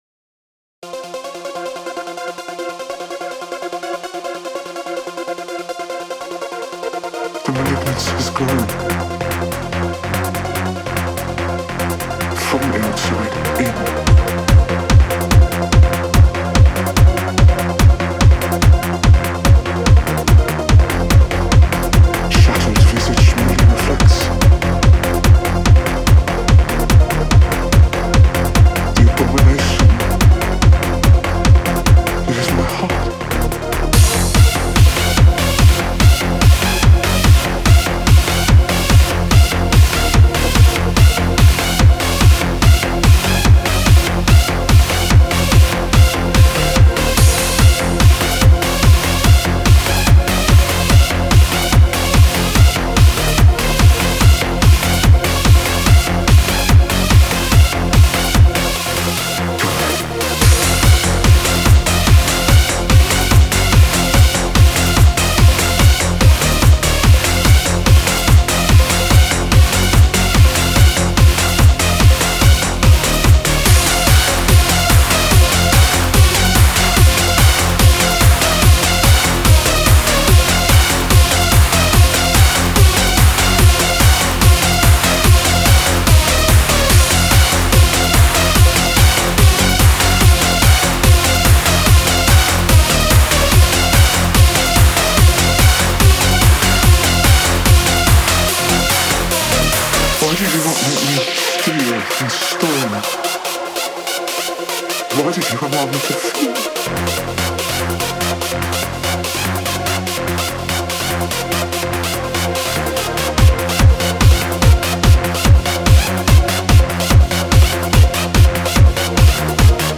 Industrial, Dark Electro
exploring the paths of clubbing goth music
Total dancefloor destroyer.